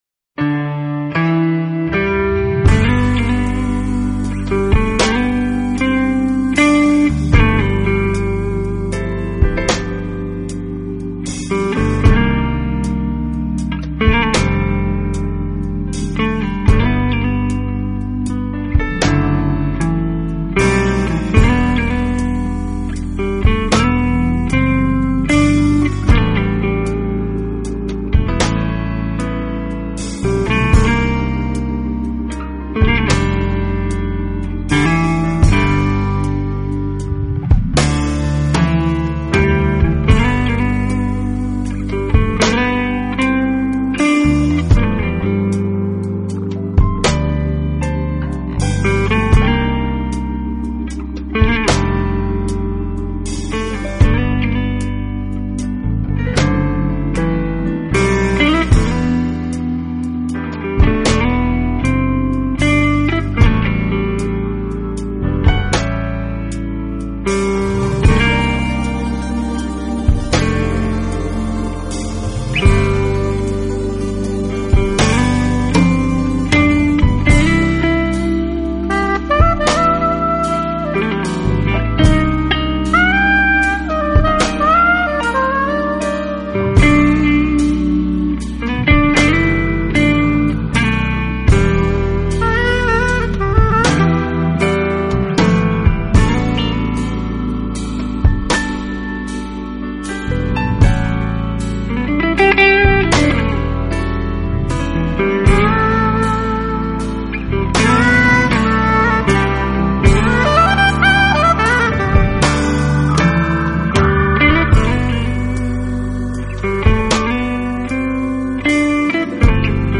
音乐风格：Jazz
的歌手，幾乎每一張專輯都由他來彈著他自己的電吉他自彈自唱。